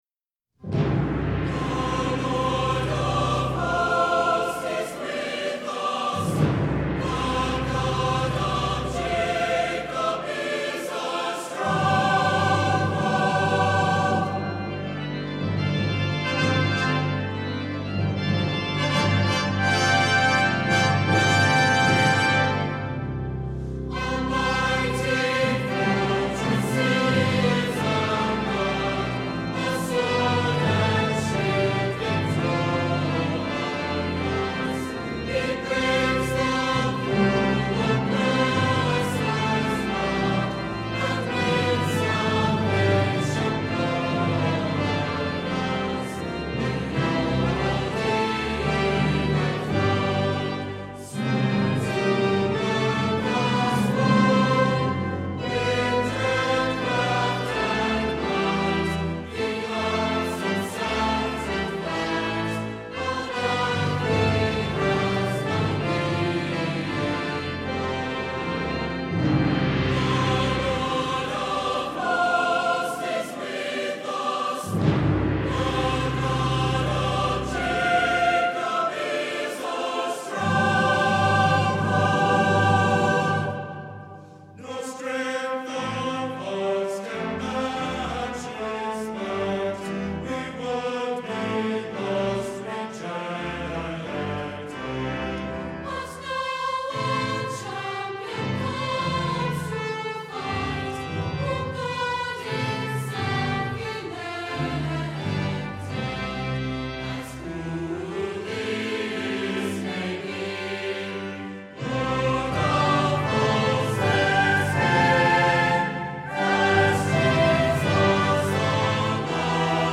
Voicing: SATB, Optional Congregation, Children's Choir